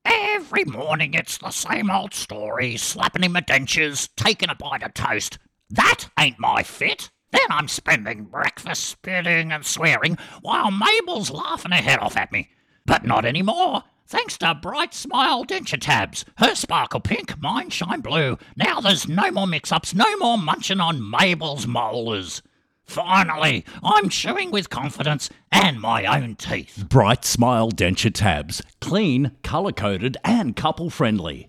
Cold read BrightSmile Denture Tabs (Cold) Produced content BrightSmile Denture Tabs